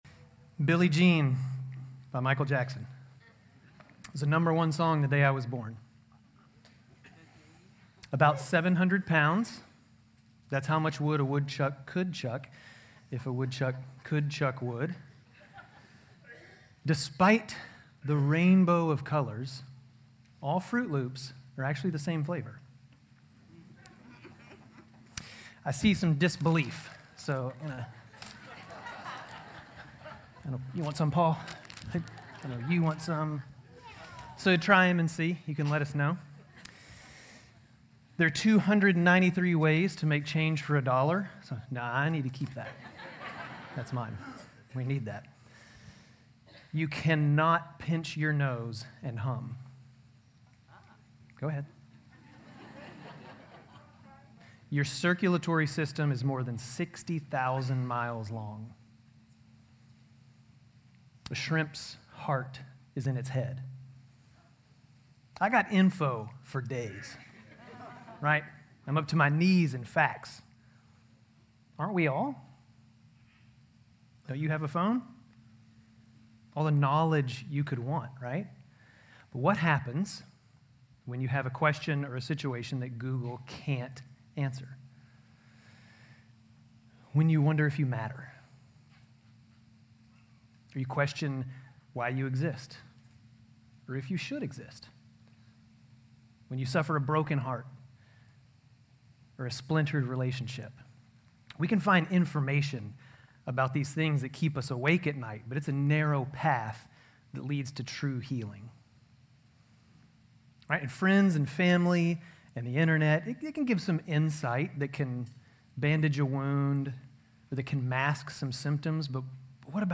GCC-Lindale-July-16-Sermon.mp3